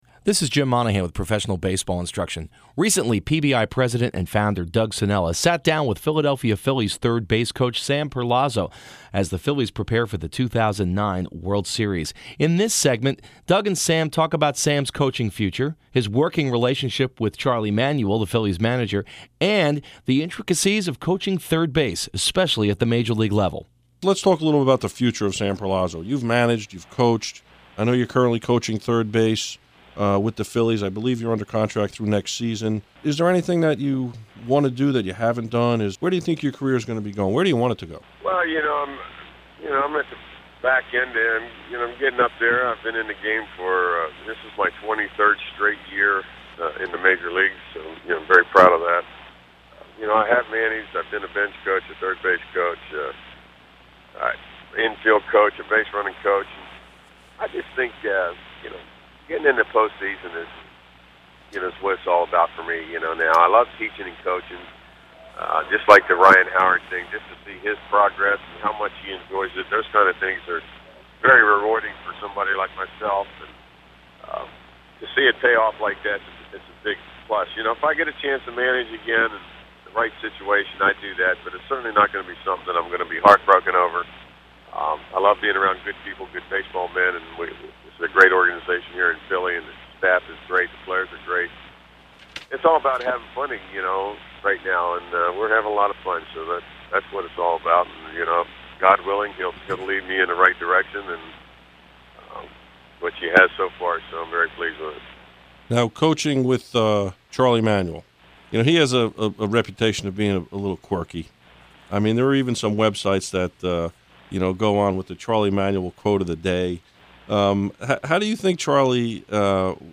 Special thanks to WDHA for their assistance in producing these interview segments.